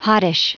Prononciation du mot hottish en anglais (fichier audio)
Prononciation du mot : hottish